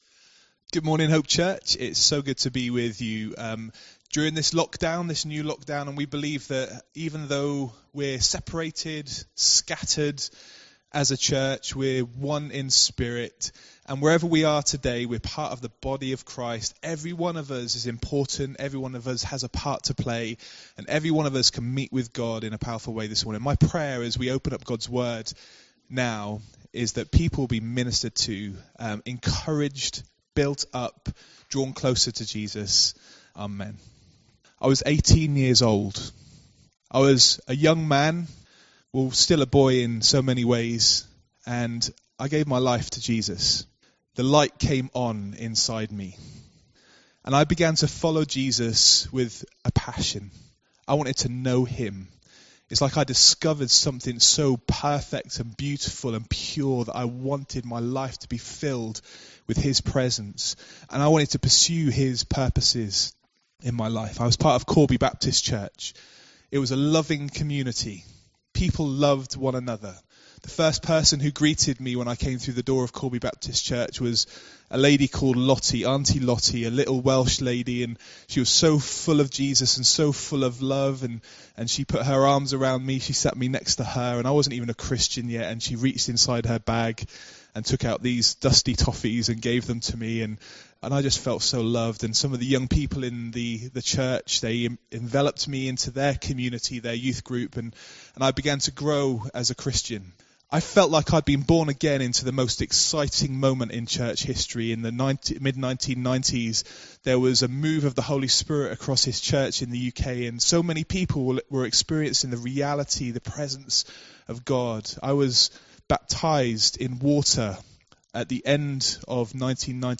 Nov 08, 2020 The Great Restoration – ‘Facing Opposition’ MP3 SUBSCRIBE on iTunes(Podcast) Notes Sermons in this Series As Christians we face all sorts of challenges and from different sources.